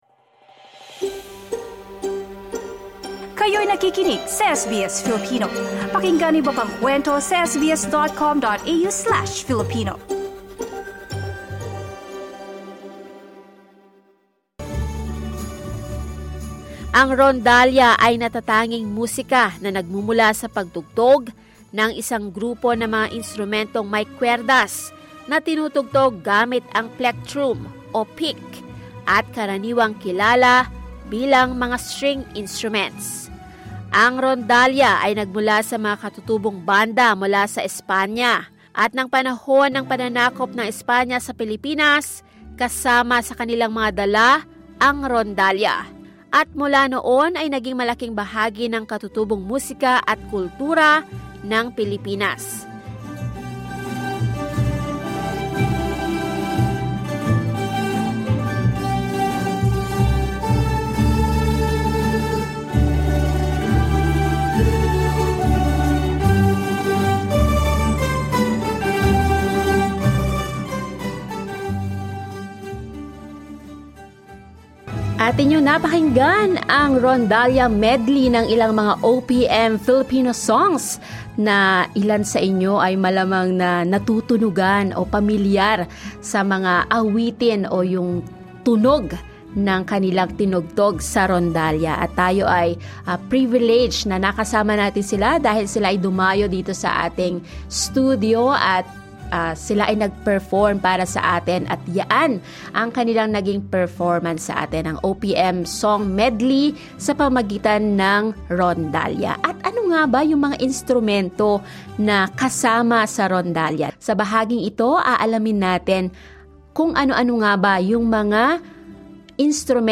Key Points Ang rondalla ay grupo ng mga instrumentong de kwerdas na bumubuo ng magandang tunog kapag sama-sama itong tinutugtog. Mga natatanging instrumentong Pilipino kabilang ang banduria, octavina, gitara, laud, bass, percussion.